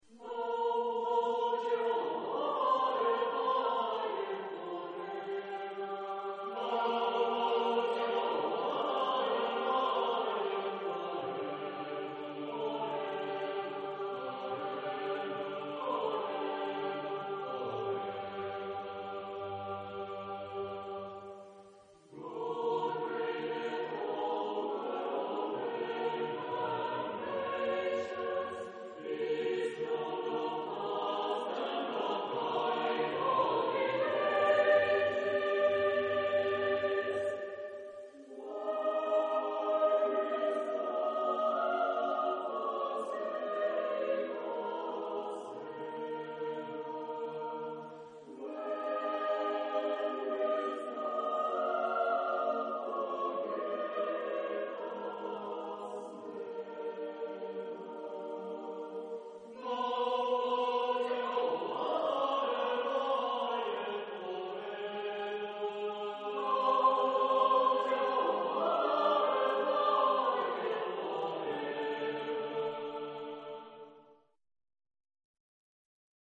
Genre-Style-Forme : Sacré ; Motet
Type de choeur : SATB  (4 voix mixtes )
Tonalité : atonal